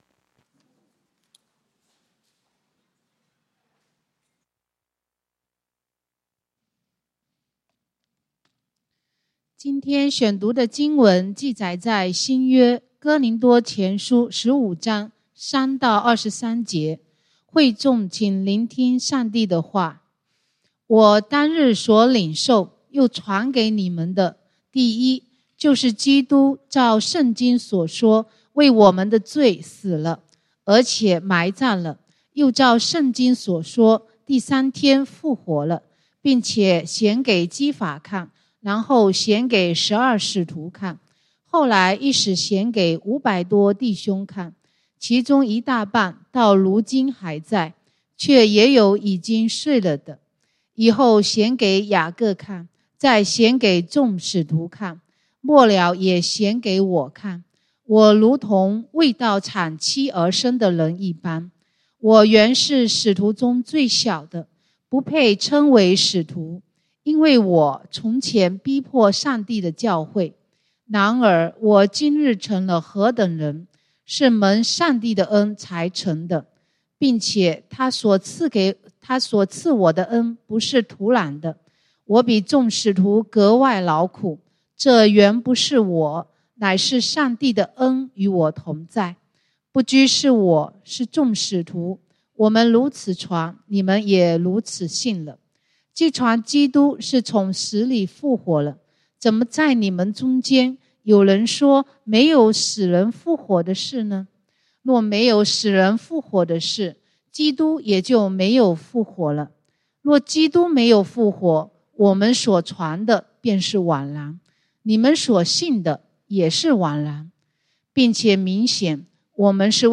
講道經文：哥林多前書 1 Corinthians 15:3-23